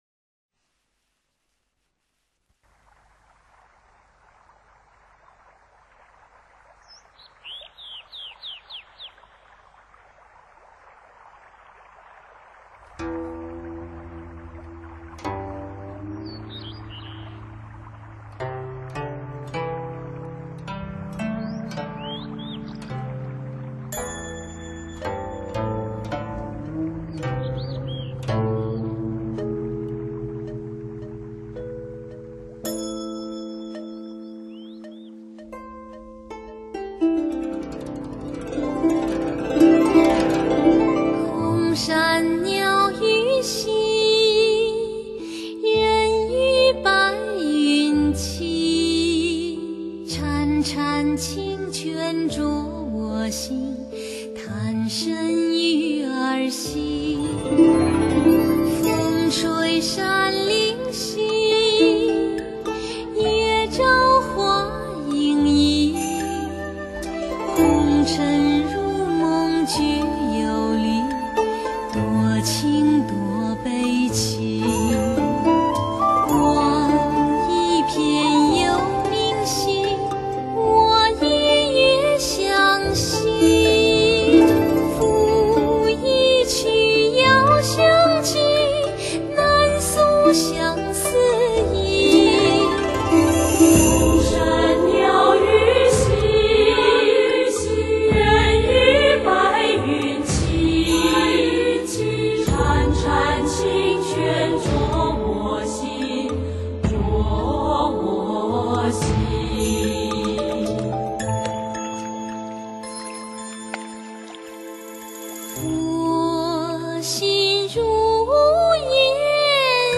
性 别： 女  星 座： 双鱼座